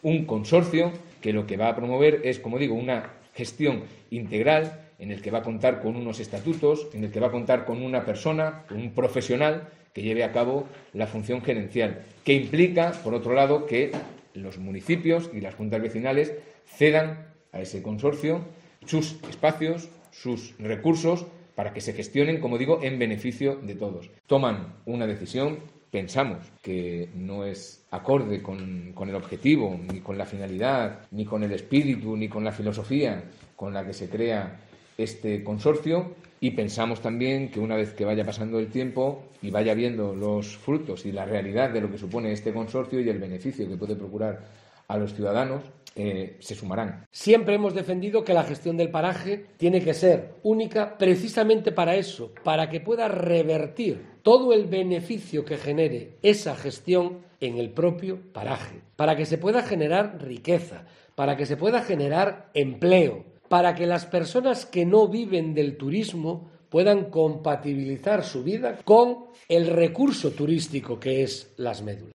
Escucha aquí las palabras del consejero de Cultura y Turismo, Javier Ortega, y del presidente del Consejo Comarcal, Gerardo Álvarez Courel